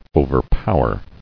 [o·ver·pow·er]